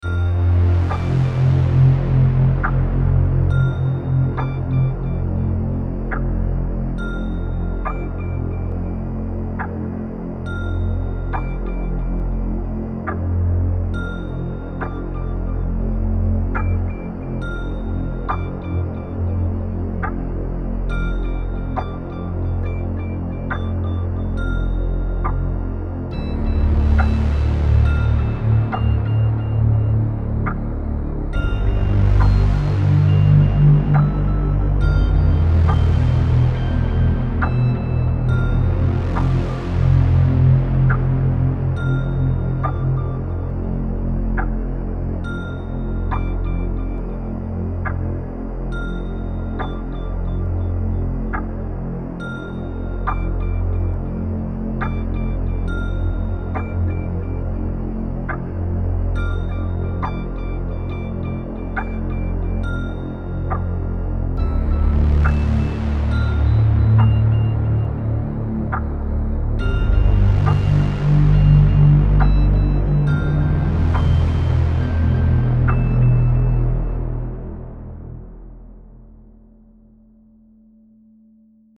Download Horror Halloween sound effect for free.
Horror Halloween